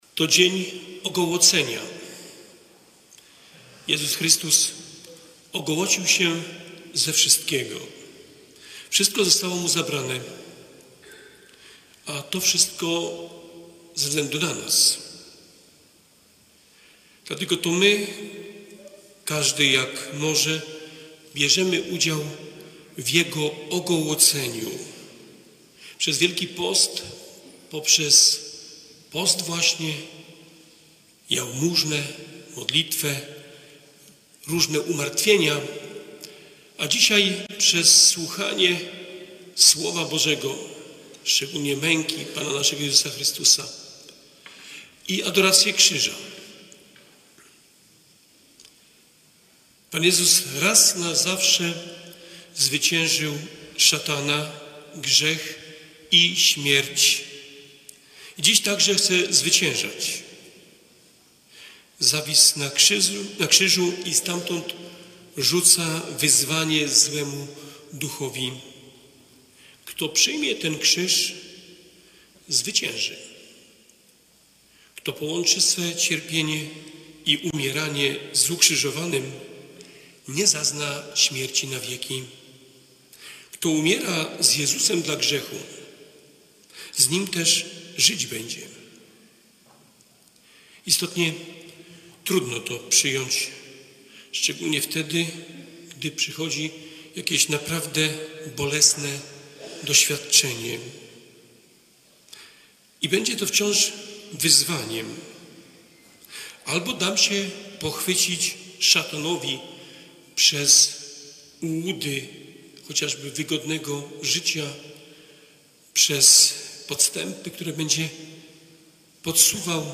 19.04 Wielki Piątek Homilia bp Włodarczyk.mp3